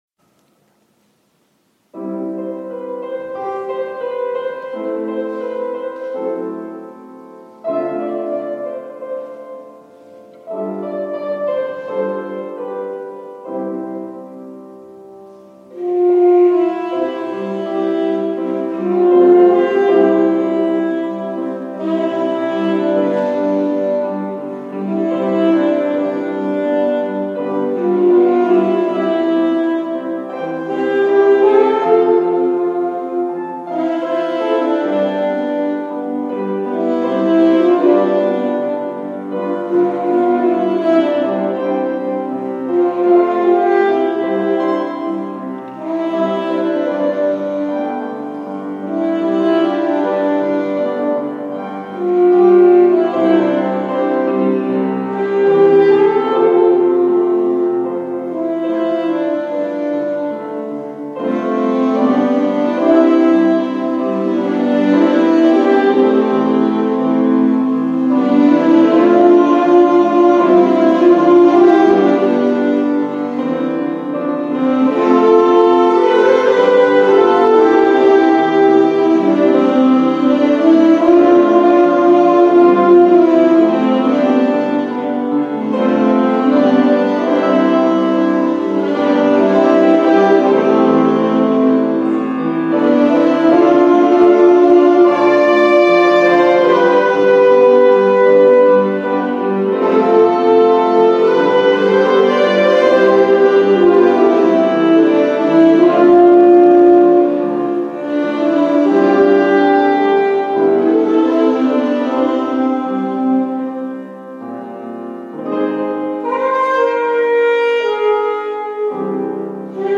instrumental.mp3